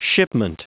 Prononciation du mot shipment en anglais (fichier audio)
Prononciation du mot : shipment